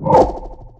spawners_mobs_teleport.2.ogg